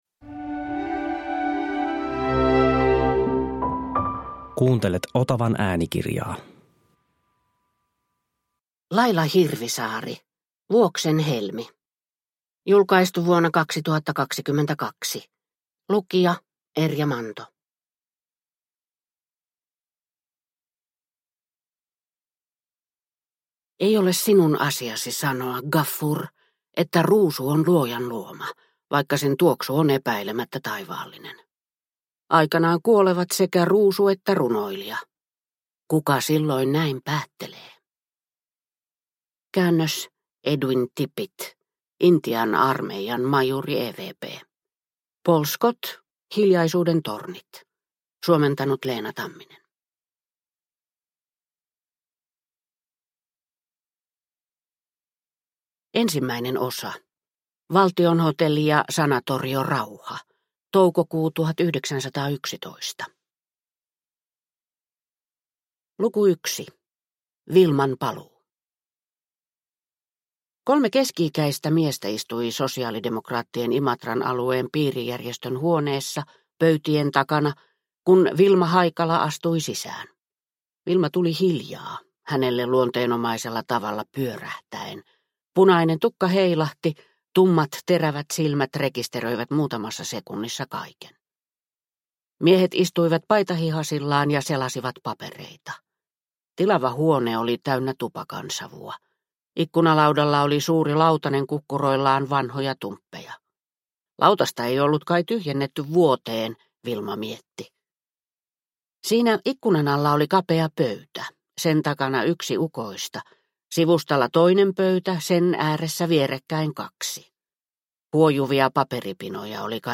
Vuoksen helmi – Ljudbok